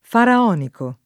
faraonico [ f ara 0 niko ]